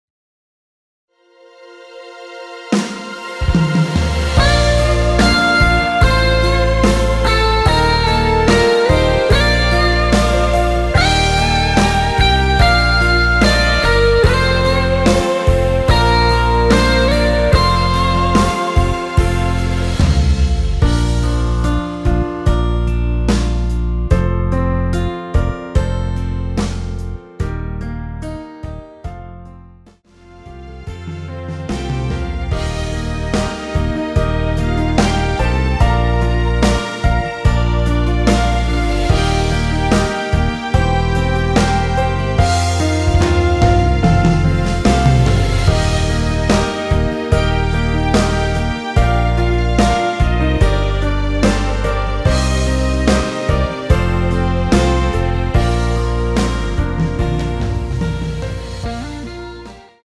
원키에서(+5)올린 MR입니다.
Bb
앞부분30초, 뒷부분30초씩 편집해서 올려 드리고 있습니다.
중간에 음이 끈어지고 다시 나오는 이유는